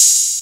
Southside Open Hatz (13).wav